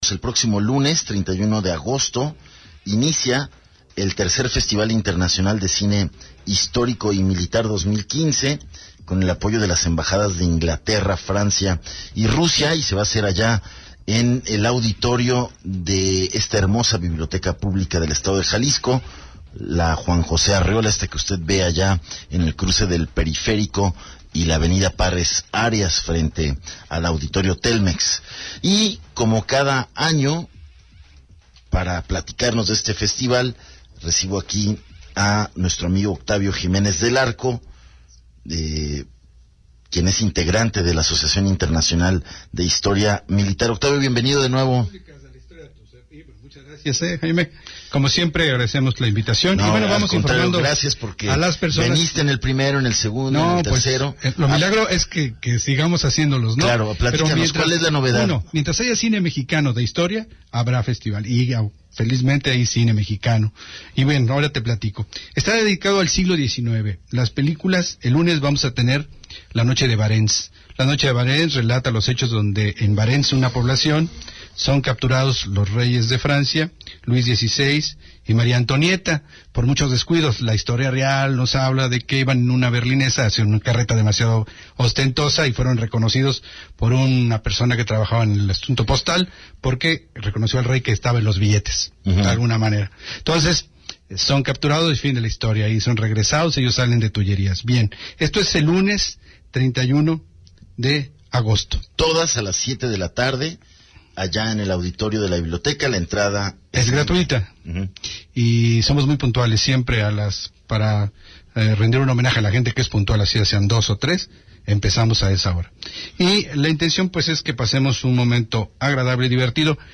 ENTREVISTA 280815